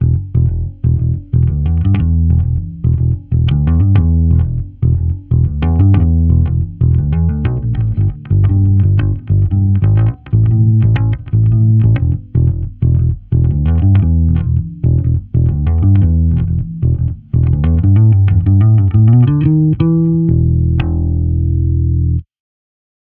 Instrument: E-Bass
Er wird hauptsächlich mit Zeig –und Mittelfinger gezupft oder angeschlagen (slappen) und auf der Bühne stehend gespielt.